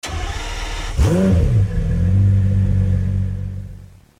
Home gmod sound vehicles tdmcars fer458
enginestart.mp3